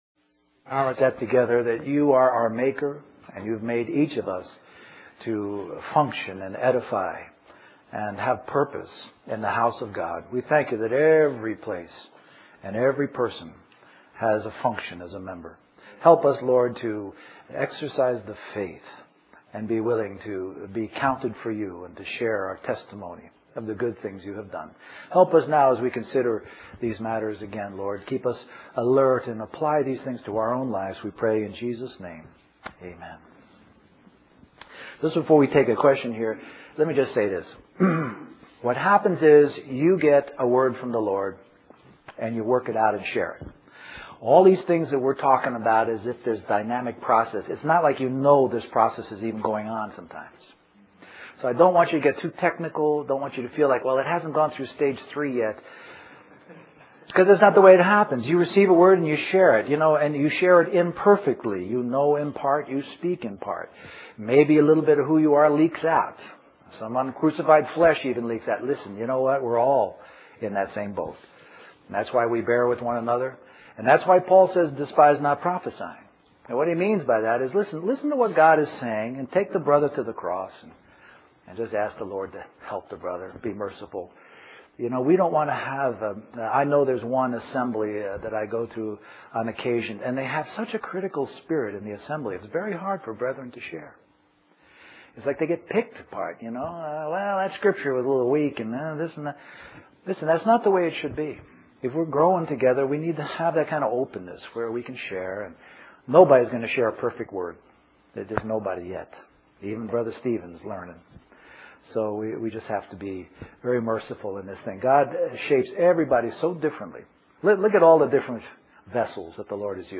This message begins and ends with a short question and answer time.